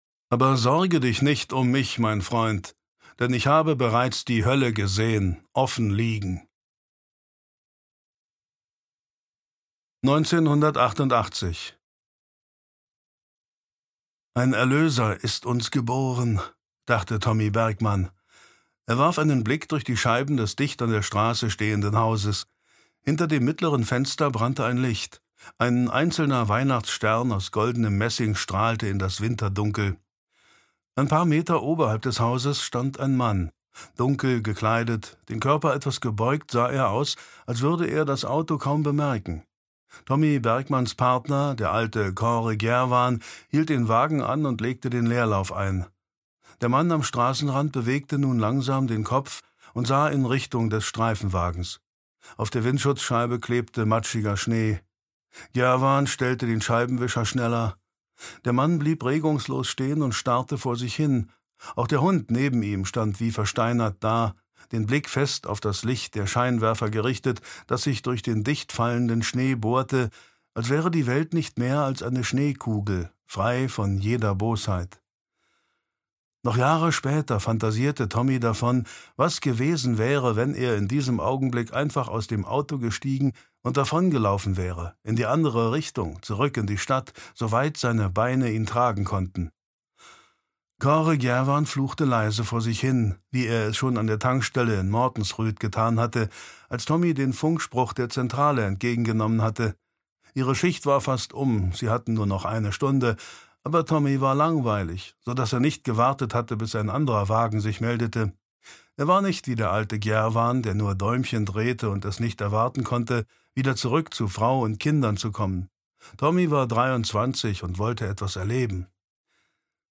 Teufelskälte (Ein Fall für Tommy Bergmann 2) - Gard Sveen - Hörbuch